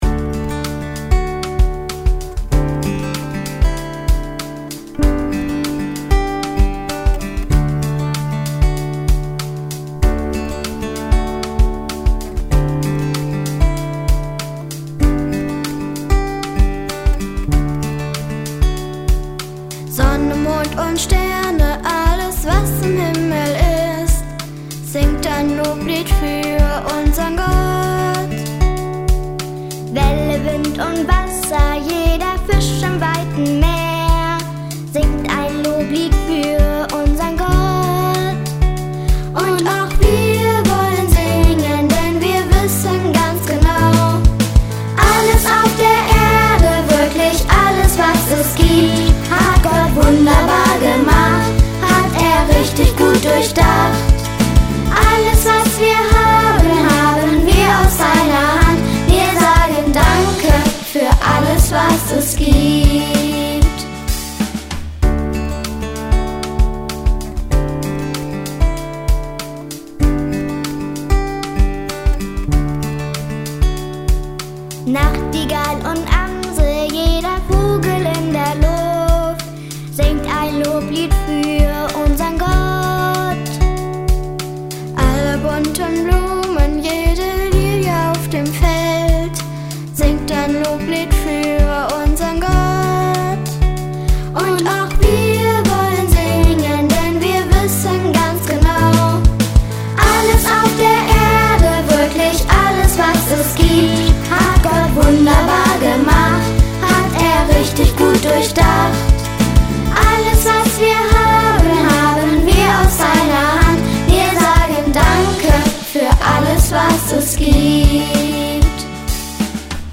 Fröhliche Popmusik für junge und jung gebliebene Gemeinde!